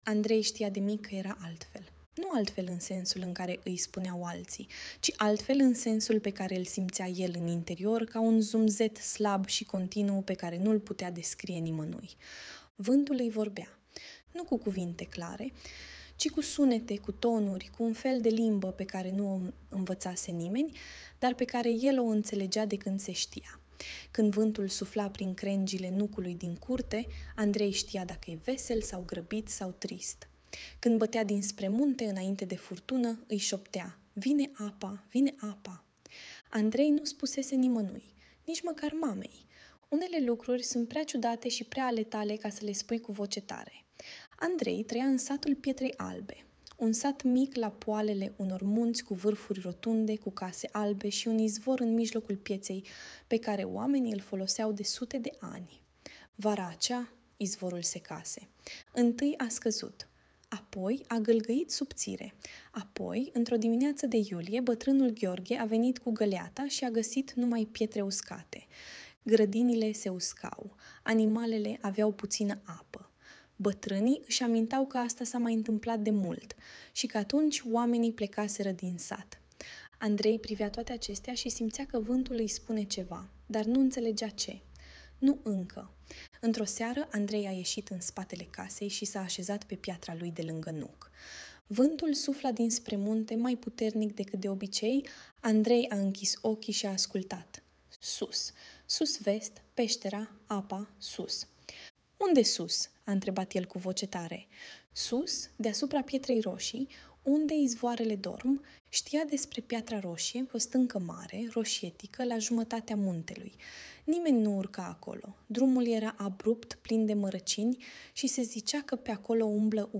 Audiobook Băiatul care vorbea cu vântul
Audiobook-Baiatul-care-vorbea-cu-vantul.mp3